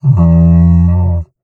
MONSTER_Groan_05_mono.wav